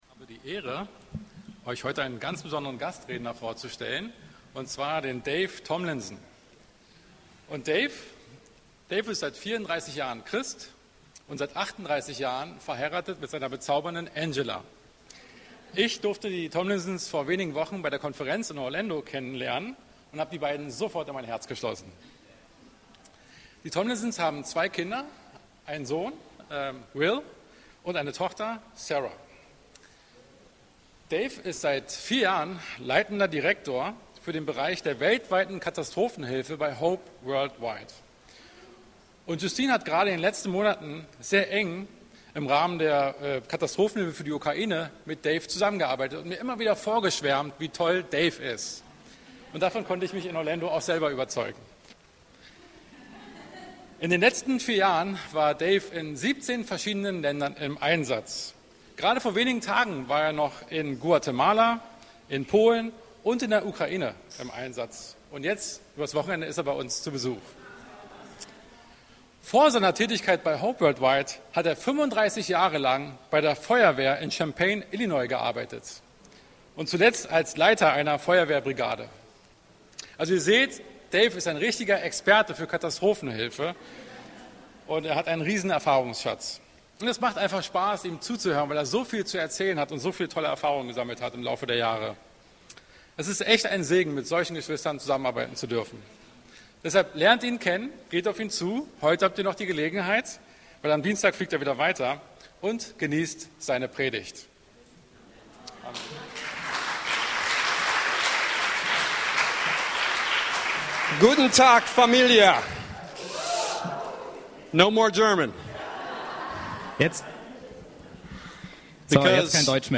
Predigten - Berliner Gemeinde Christi